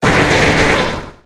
Cri de M. Glaquette dans Pokémon HOME.